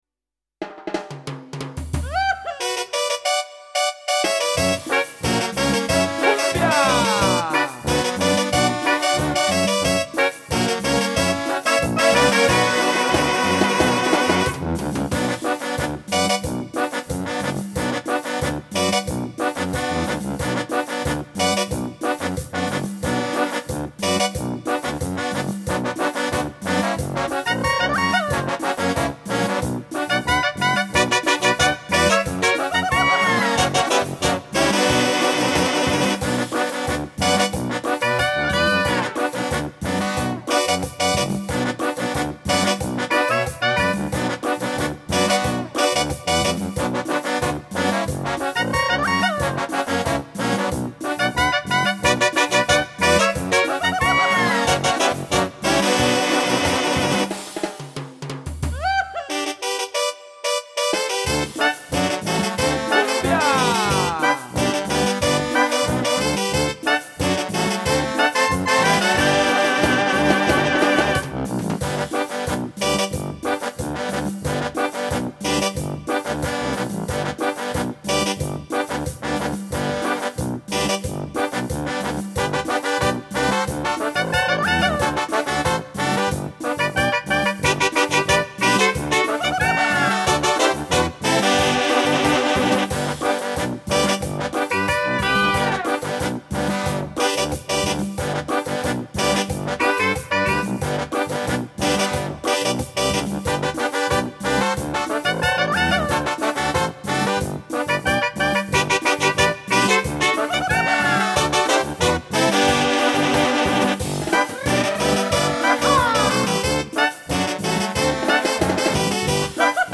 Home > Music > Jazz > Bright > Smooth > Folk